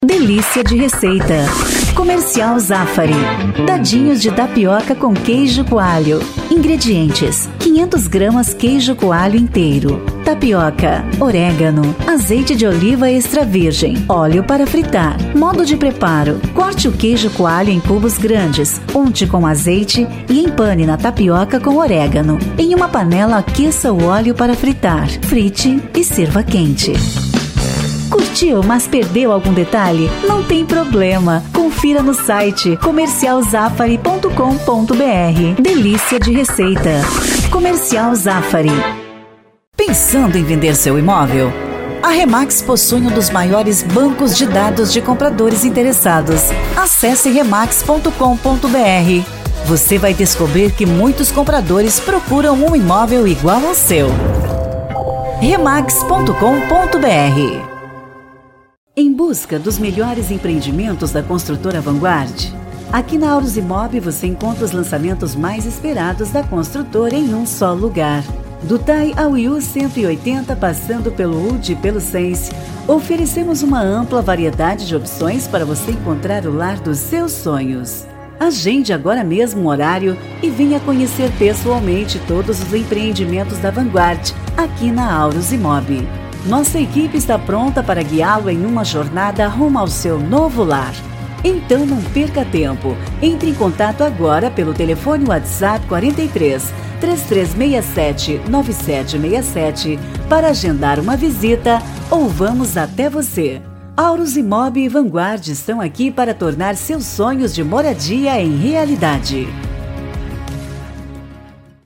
Spot Comercial
Vinhetas
Estilo(s):
Animada